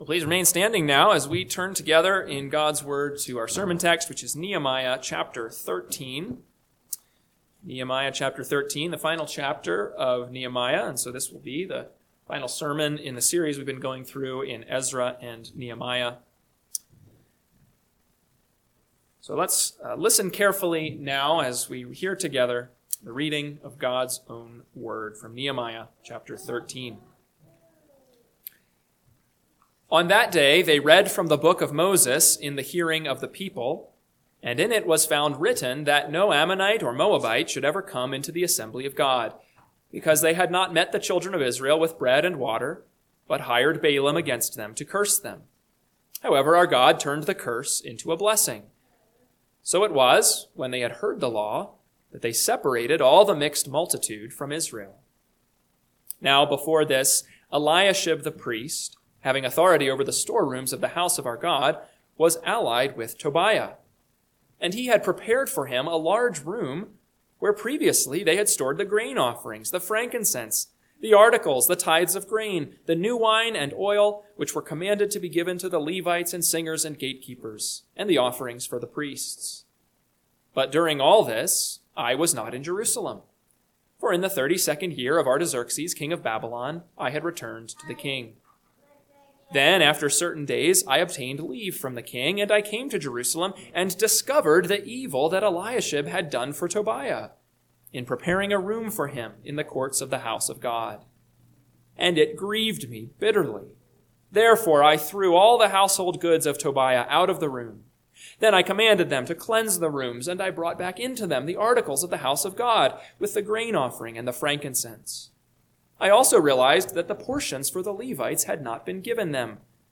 PM Sermon – 11/16/2025 – Nehemiah 13 – Northwoods Sermons